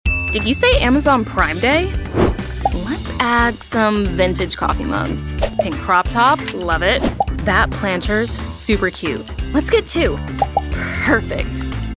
Commercial & problem Corporate Work
It’s Prime Day — Cool, Upbeat, Friendly
Commercial-Snippet-Prime-Day.mp3